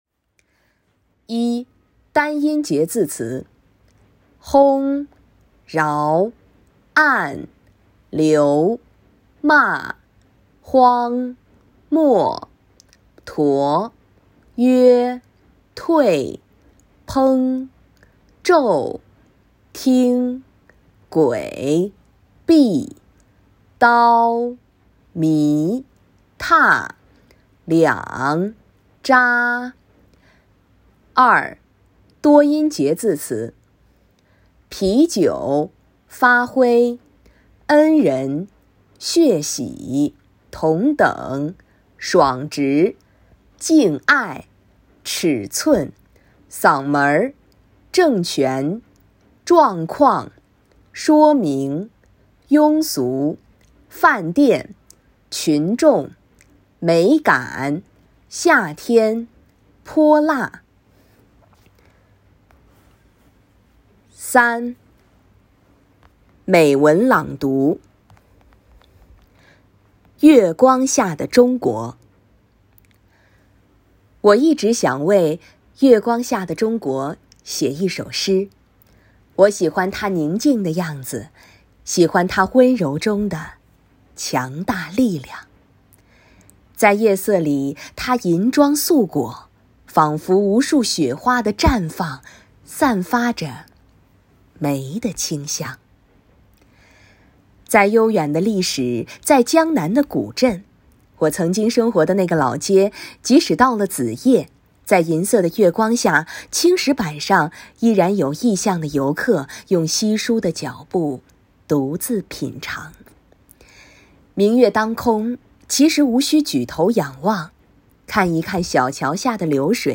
（领读音频于文末，可播放跟读练习）